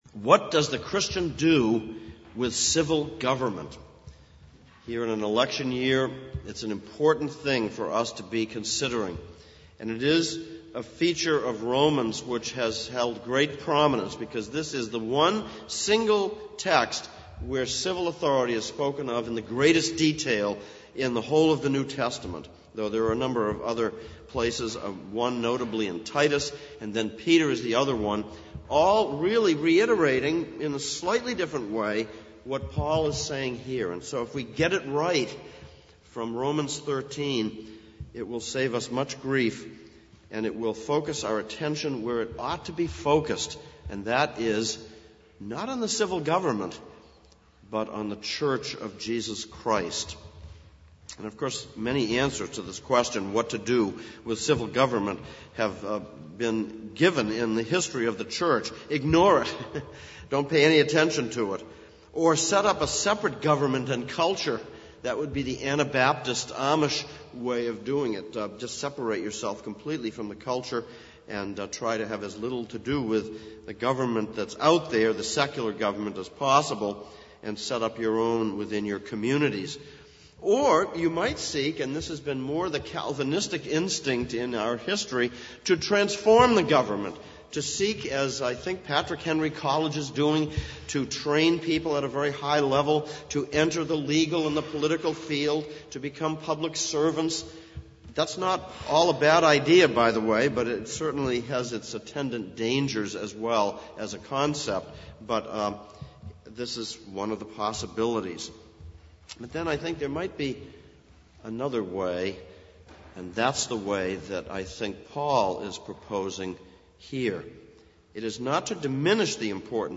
Exposition of Romans Passage: Romans 13:1-7 Service Type: Sunday Morning « 03.